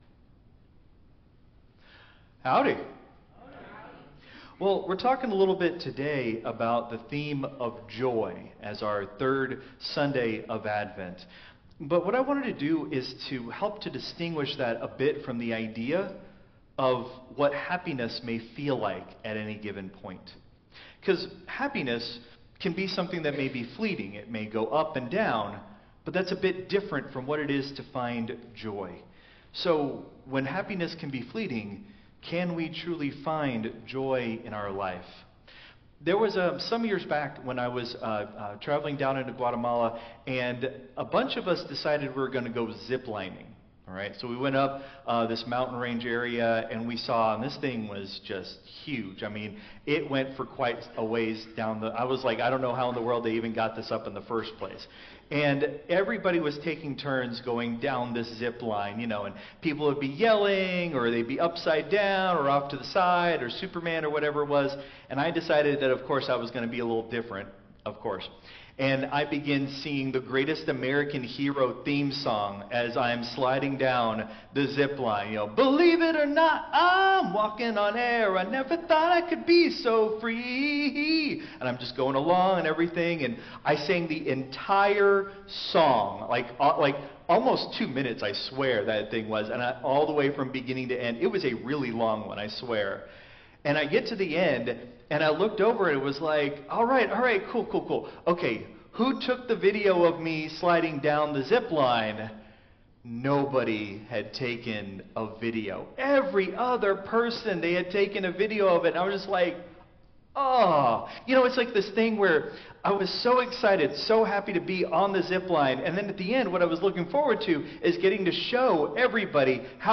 Christ Memorial Lutheran Church - Houston TX - CMLC 2024-12-15 Sermon (Contemporary)